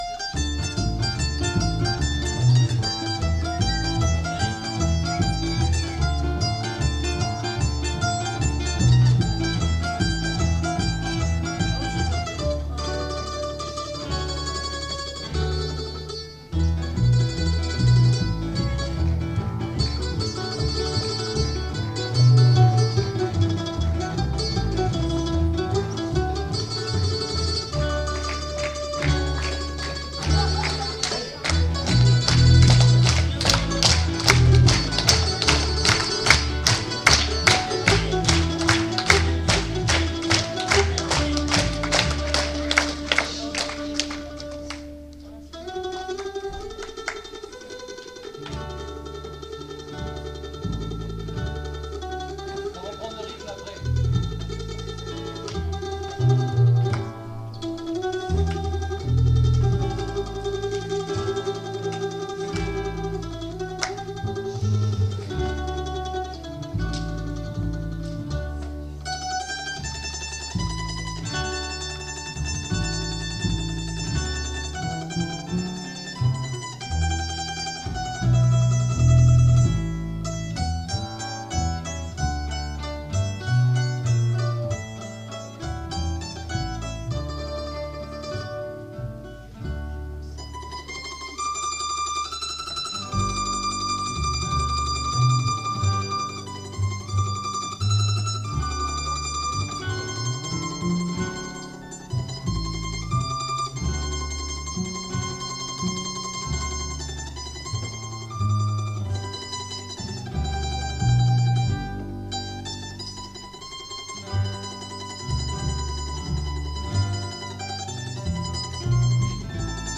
musique napolitaine
mandoline, contrebasse et guitare
extrait du concert